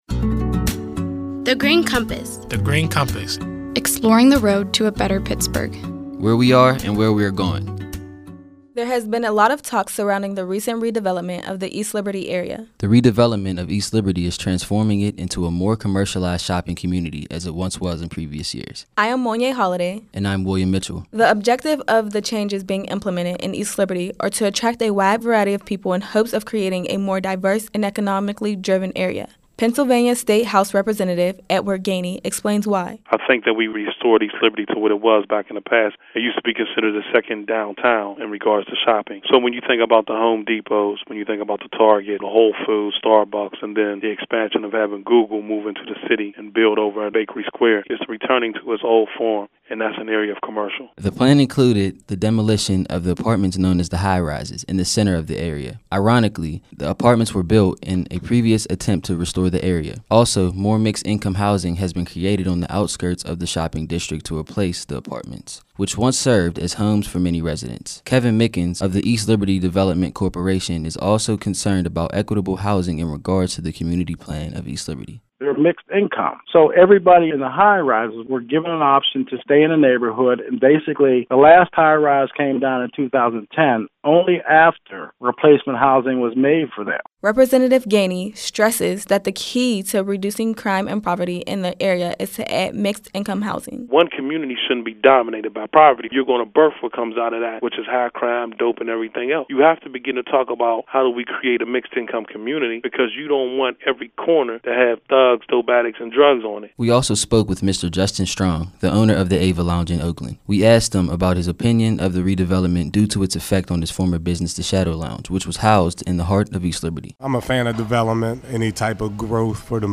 In July 2014, thirty-two recent high-school graduates created these radio features while serving as Summer Interns at The Heinz Endowments.